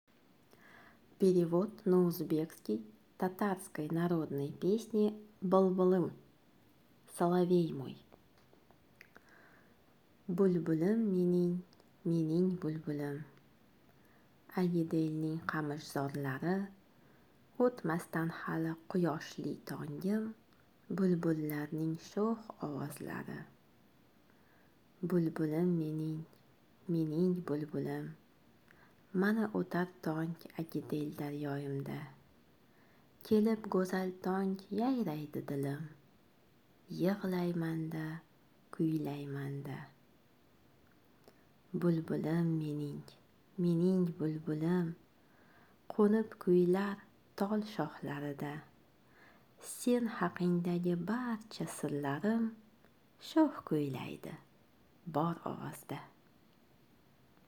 Перевод на узбекский язык татарской/башкирской народной песни «Былбылым»